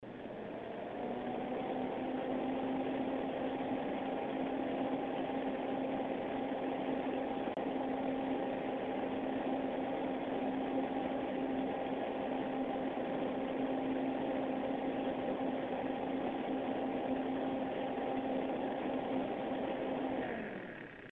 Acoustic Samples: Listen to this Heatsink!
The Coolermaster Hyper 212's 120mm fan spins at a fixed fan speed of 2000RPM, generating a moderate amount of noise.
frostytech acoustic sampling chamber - full speed
standard waveform view of a 10 second recording. click on the headphones icon to listen to an mp3 recording of this heatsink in operation. the fan is rotating at 2000 rpm